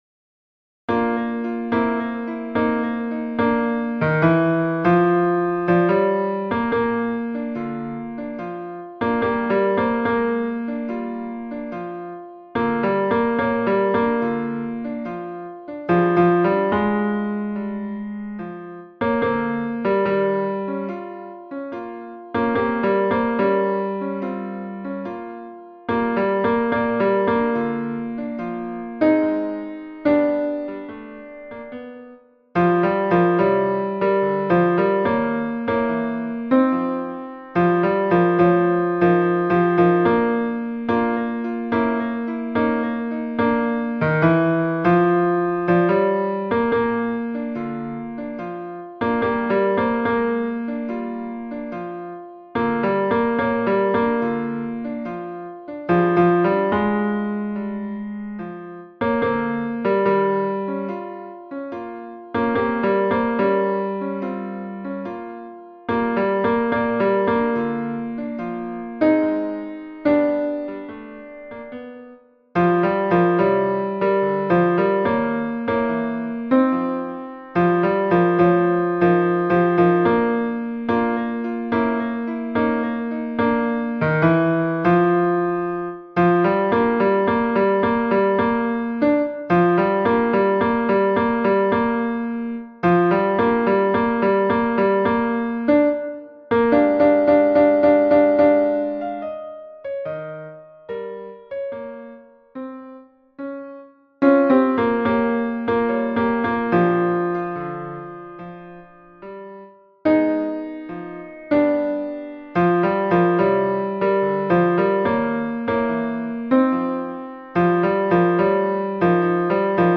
MP3 version piano
Ténor